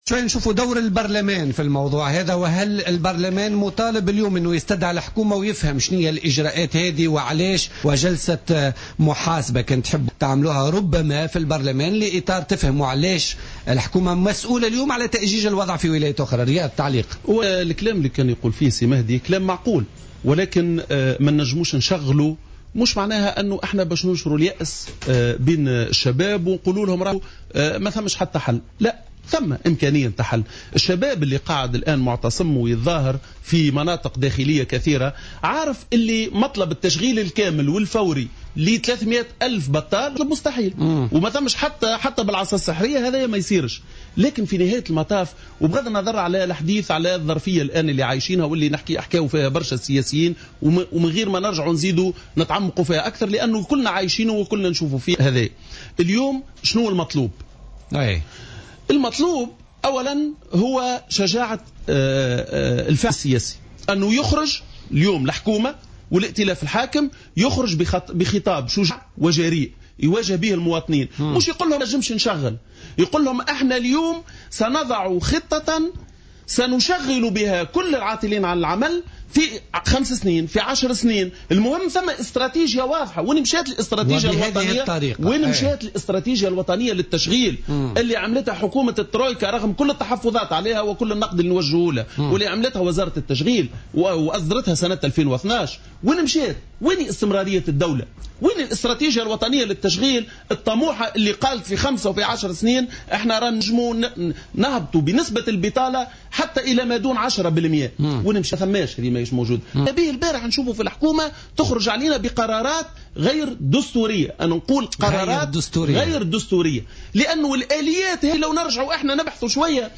واستنكر على "الجوهرة أف أم" ببرنامج "بوليتيكا"، اعتماد الحكومة الحالية آليات تشغيل أرساها النظام السابق تتعلق بآليات التشغيل الهش والتي يفترض رفضها من الجميع وعلى رأسهم اتحاد الشغل، بحسب تعبيره.